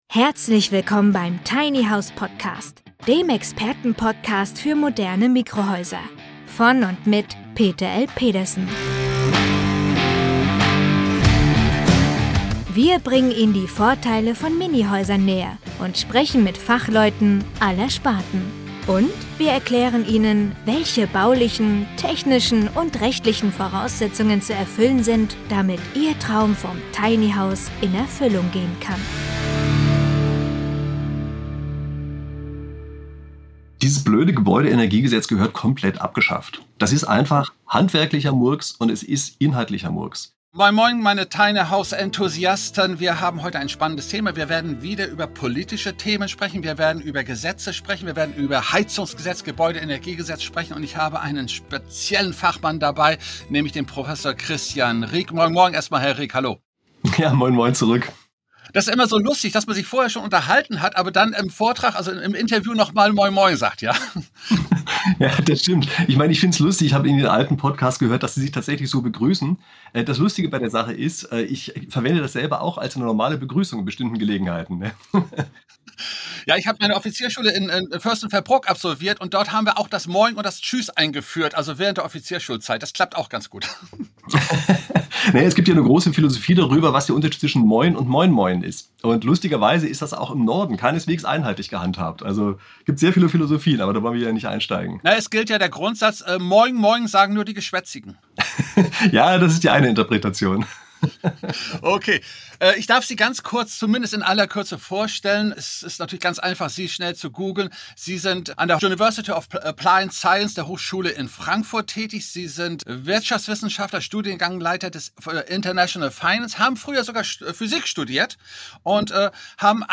im Gespräch zum Gebäudeenergiegesetz.